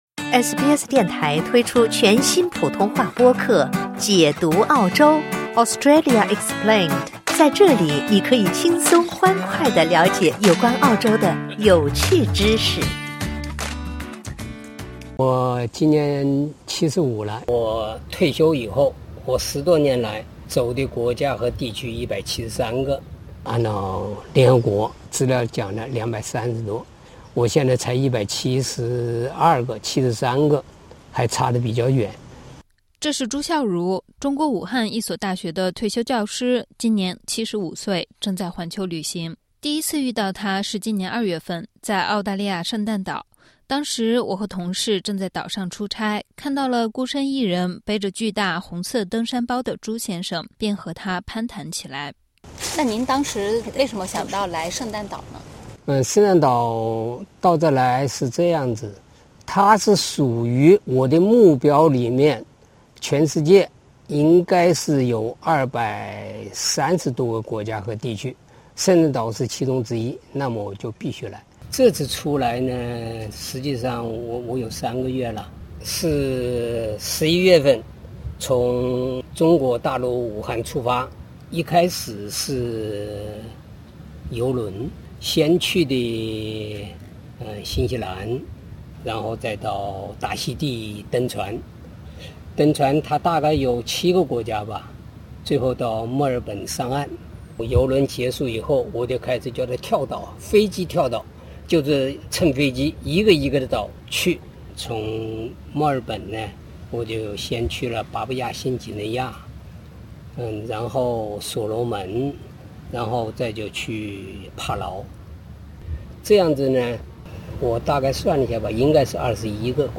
Source: Supplied 他在马达加斯加的首都塔那那利佛再次接受了SBS中文的采访。